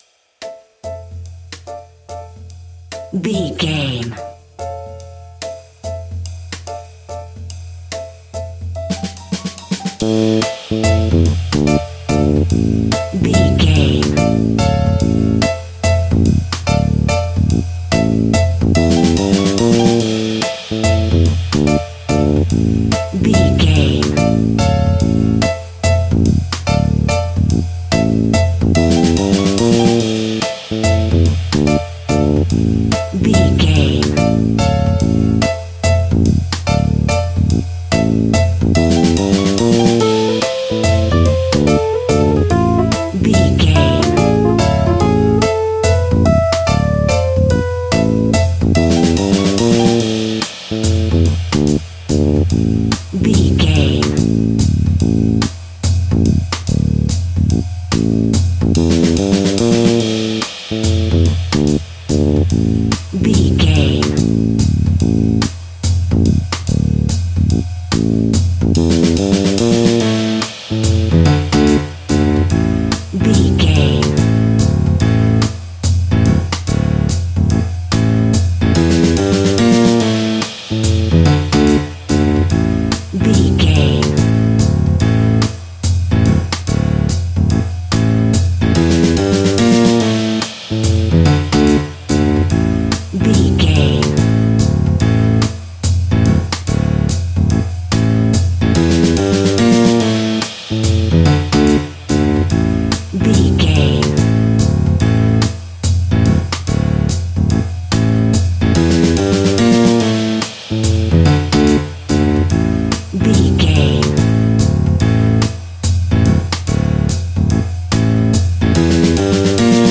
Aeolian/Minor
ominous
haunting
eerie
playful
electric piano
drums
bass guitar
synthesiser
spooky
horror music